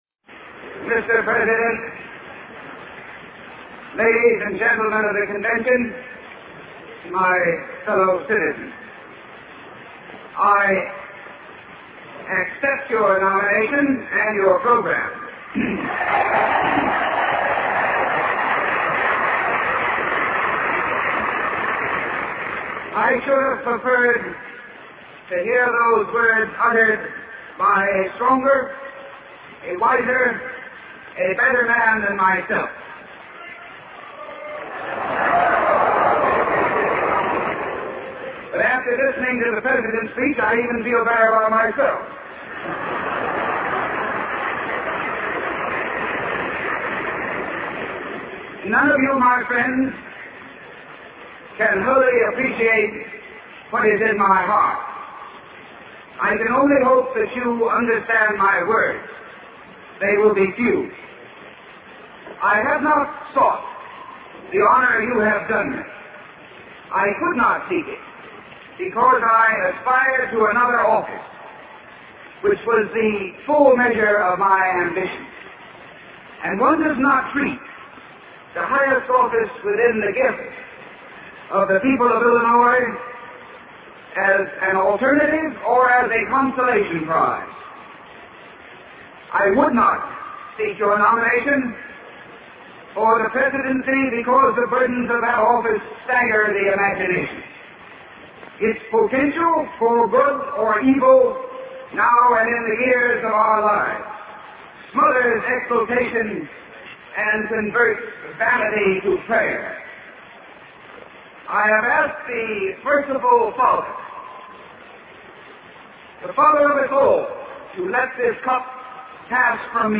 Speech Accepting the Democratic Presidential Nomination